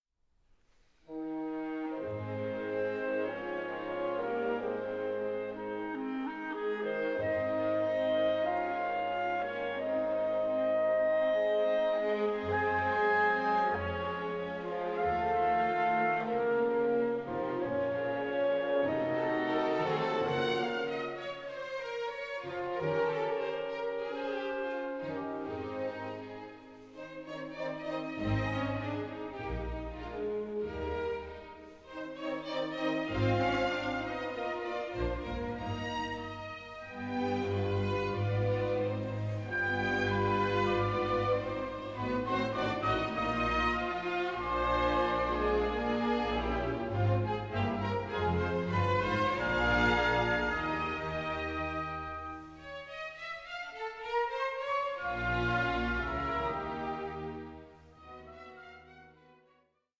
Nicht schnell 4:53